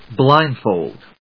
音節blínd・fòld 発音記号・読み方
/ˈblaɪˌndfold(米国英語), ˈblaɪˌndfəʊld(英国英語)/